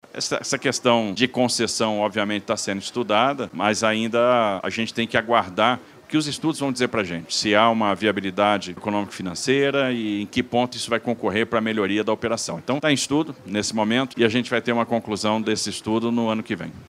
Foi o que disse o governador de São Paulo, Tarcísio de Freitas, na manhã desta quinta-feira, 29 de fevereiro de 2024, durante cerimônia de chegada da tuneladora (tatuzão) na estação Vila Formosa, na zona leste da capital paulista, que atua nas obras da primeira fase da expansão da linha 2-Verde.